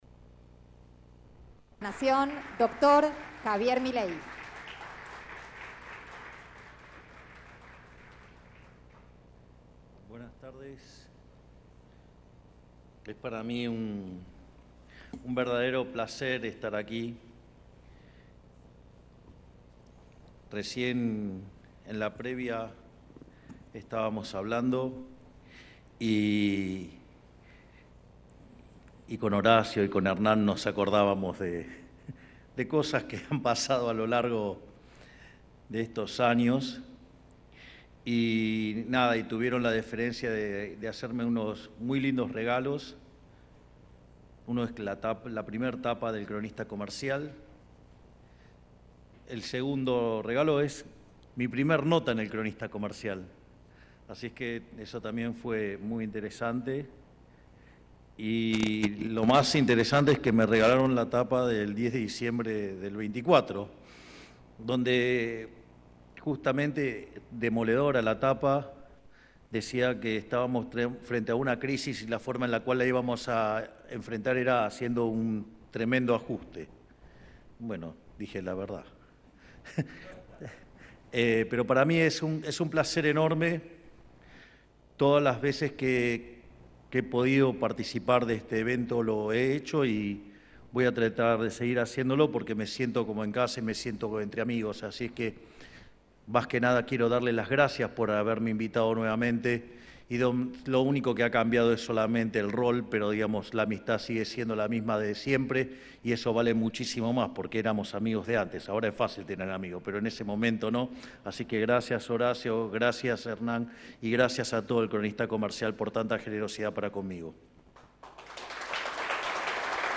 Milei-en-el-Encuentro-de-Lideres-del-Cronista.mp3